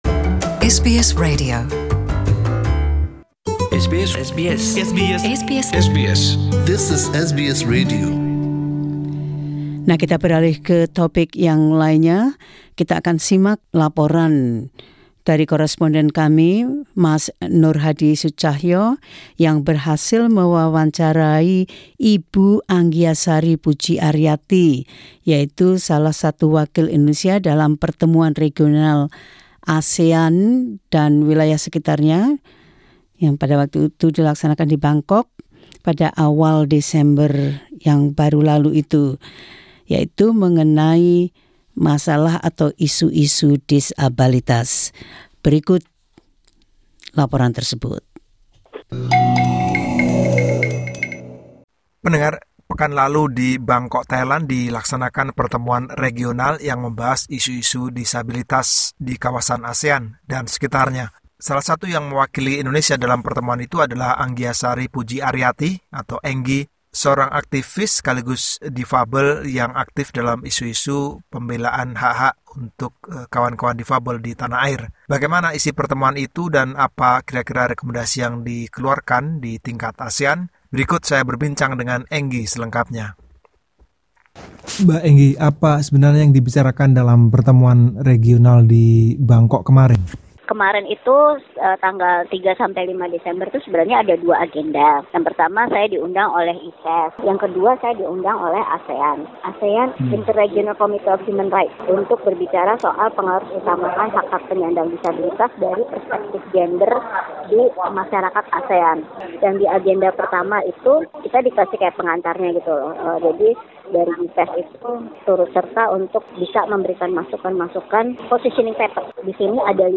wawancaranya